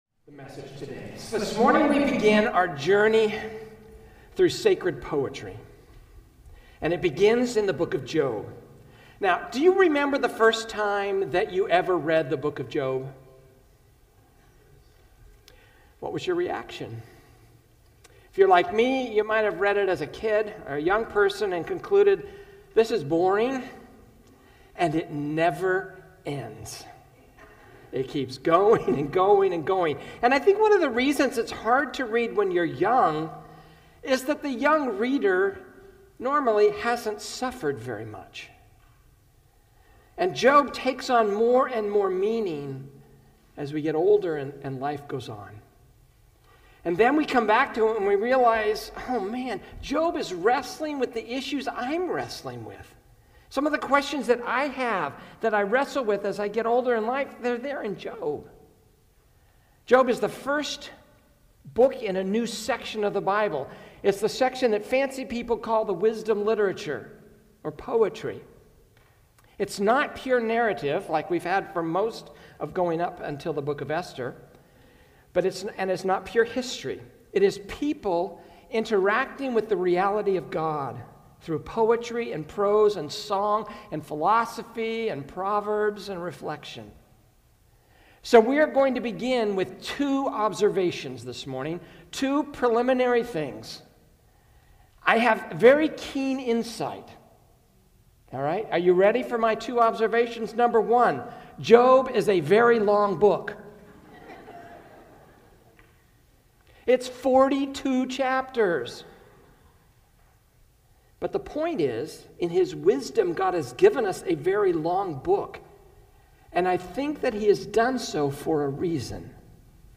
A message from the series "Timeless."